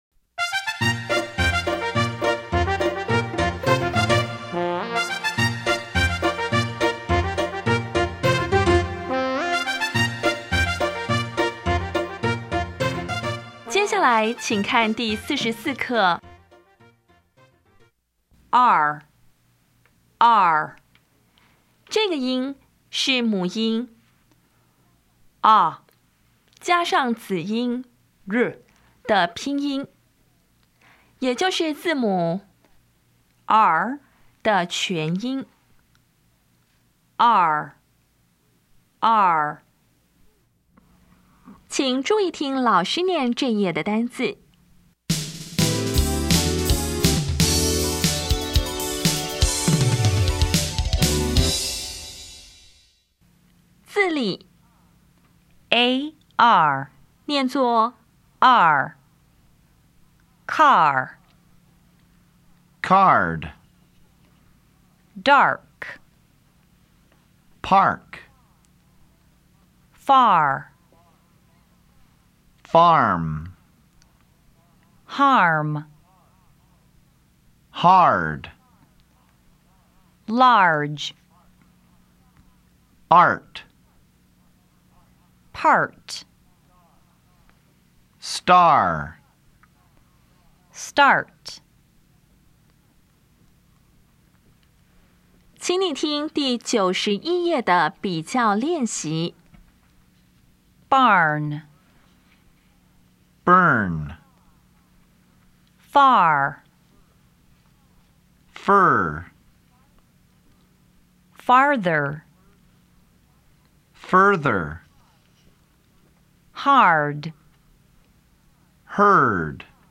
当前位置：Home 英语教材 KK 音标发音 母音部分-3: 双母音 [ɑr]
[ɑr]
音标讲解第四十四课
比较 [ɑr][ɝ]